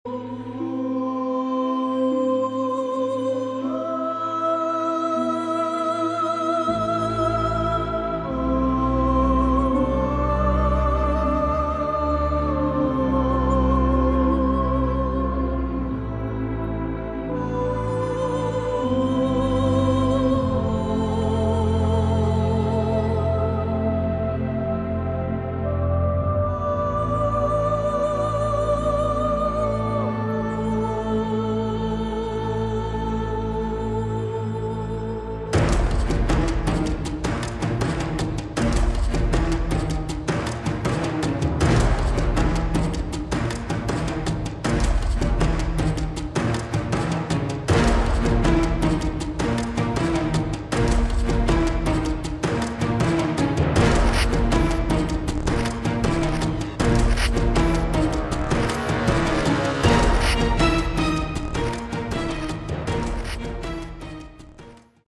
Category: Melodic/Symphonic Metal
guitars, keyboards and orchestral arrangements
lead and backing vocals